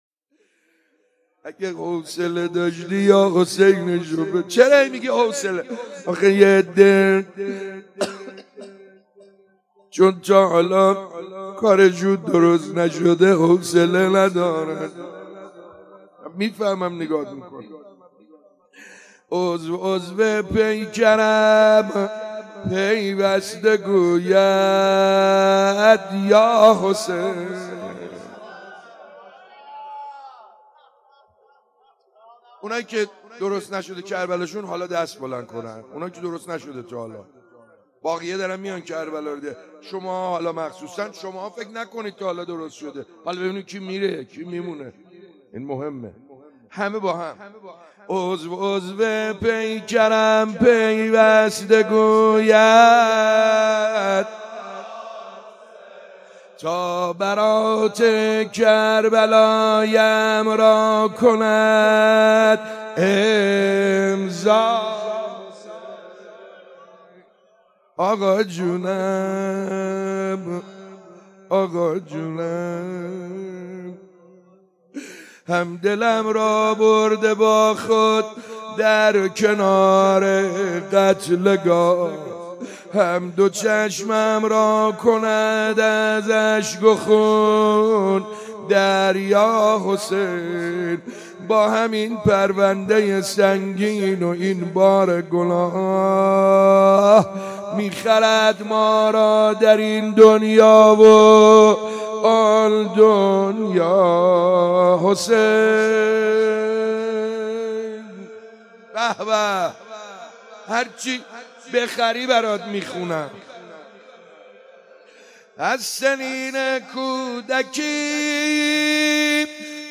20 آبان 95_روضه_بخش اول